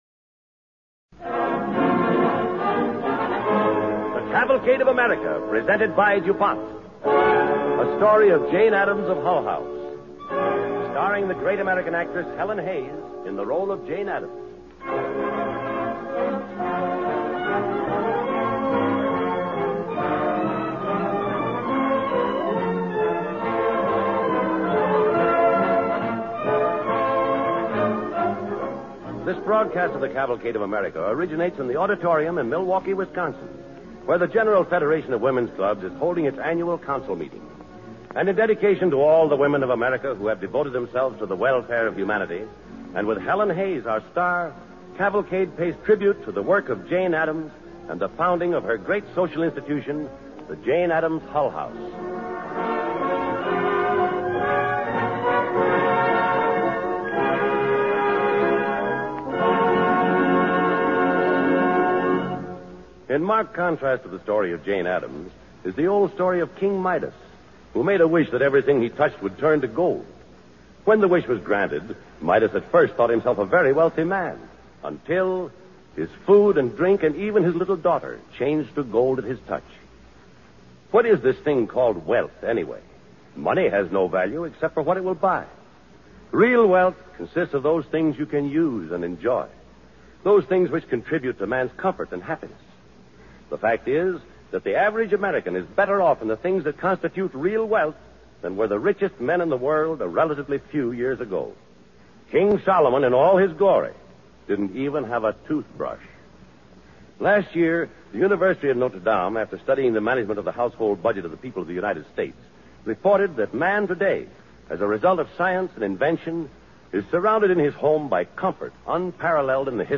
Cavalcade of America Radio Program
Jane Addams of Hull House, starring Helen Hayes